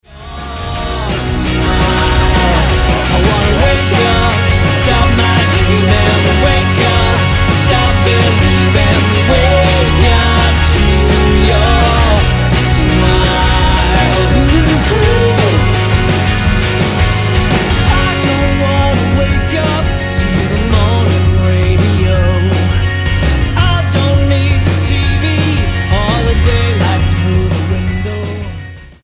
Roots/Acoustic
Style: Pop